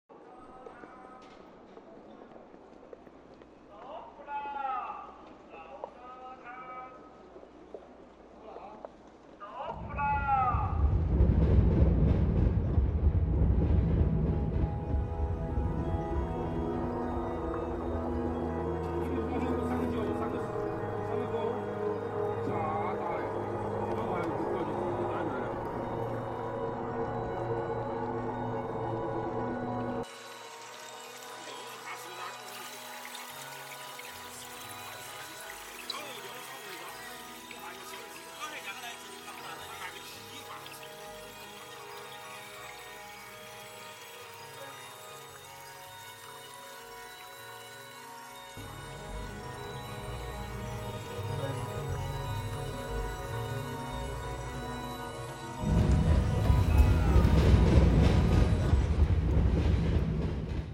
Sounds from home (elsewhere) are overlaid and geo-located along the canal.